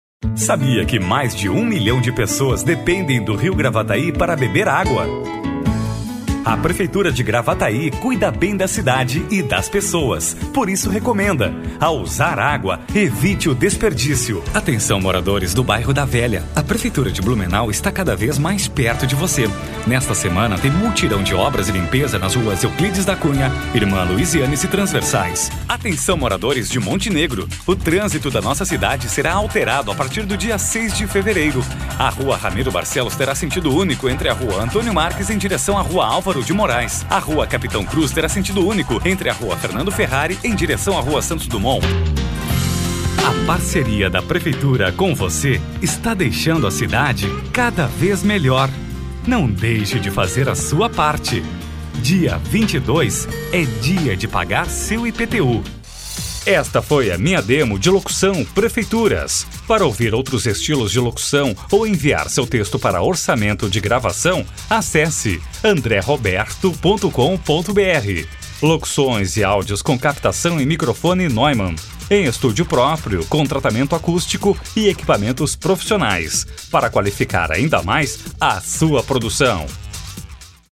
Professional announcer for more than 20 years, with his own studio and professional equipment, using microphone Neumann TLM 103, the guarantee of a great work.
Sprechprobe: Sonstiges (Muttersprache):